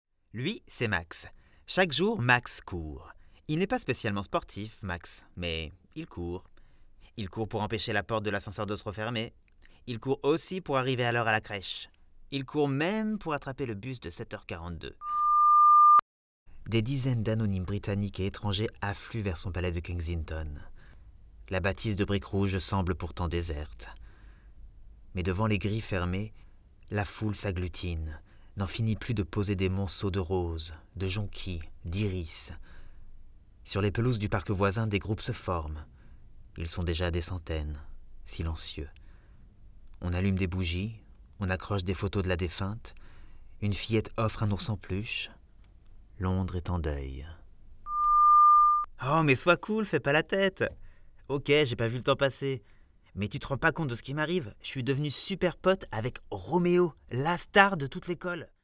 Voix démo
15 - 45 ans - Baryton Ténor